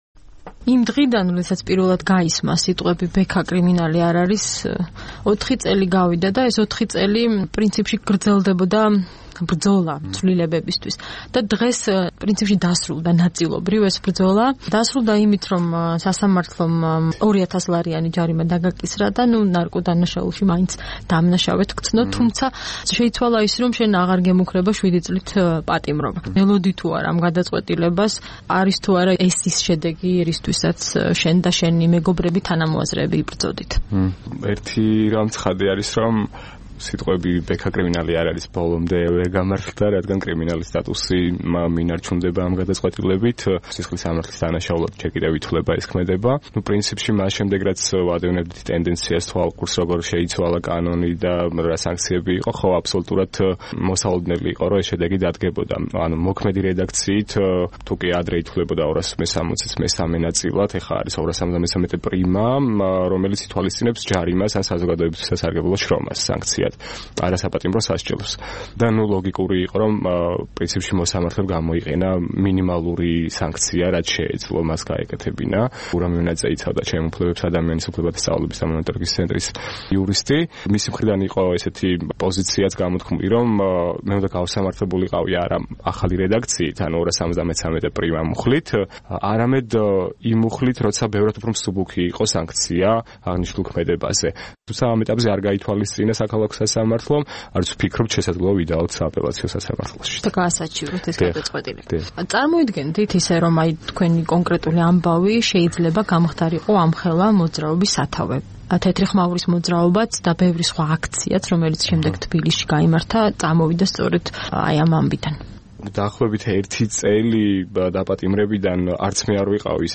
ინტერვიუ